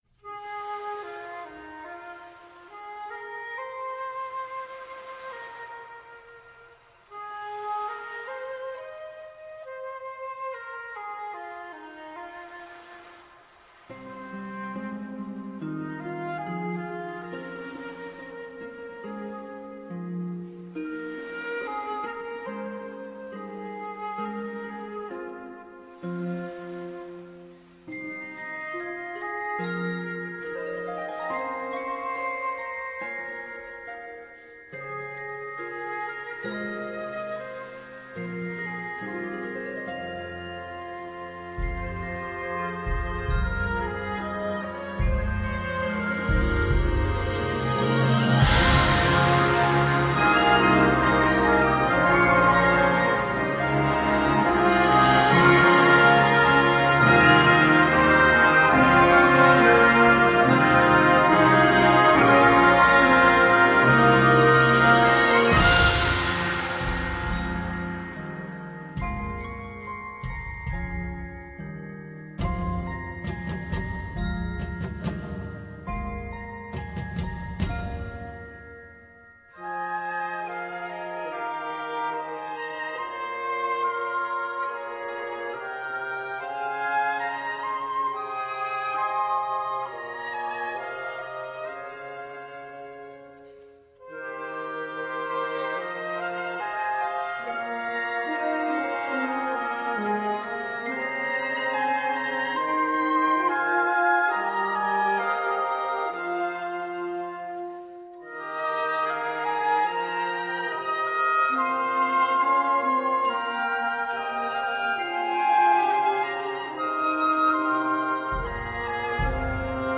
Besetzung: Blasorchester
A tour de force of exotic sounds and emotional design